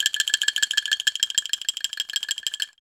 Index of /90_sSampleCDs/NorthStar - Global Instruments VOL-2/PRC_Angklungs/PRC_Angklungs